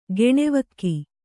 ♪ geṇevakki